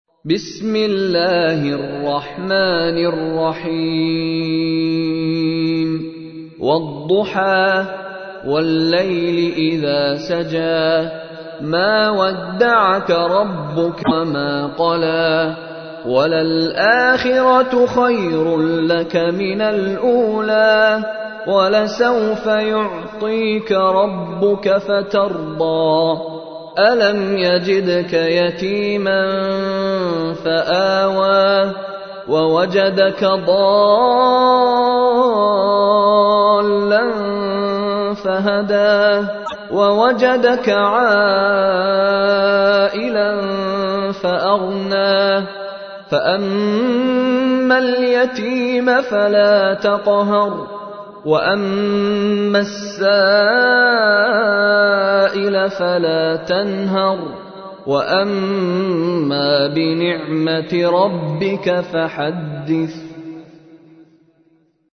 تحميل : 93. سورة الضحى / القارئ مشاري راشد العفاسي / القرآن الكريم / موقع يا حسين